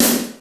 Power_sd.wav